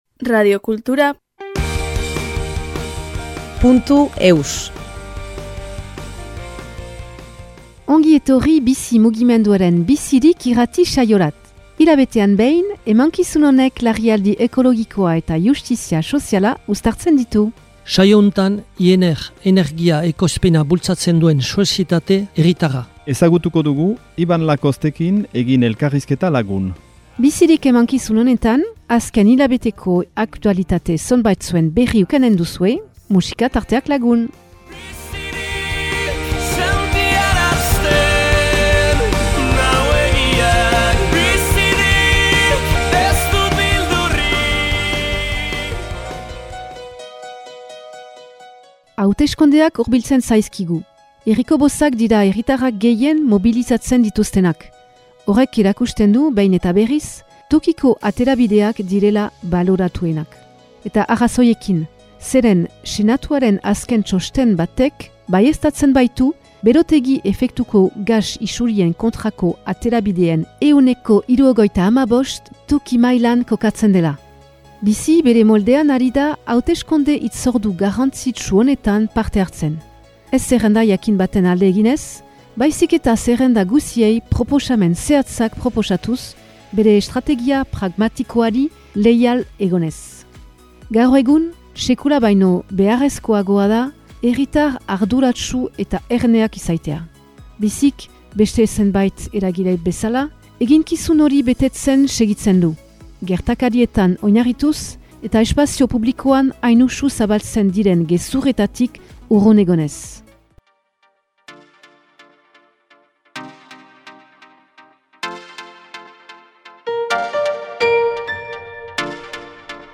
#133 Bizirik irrati saioa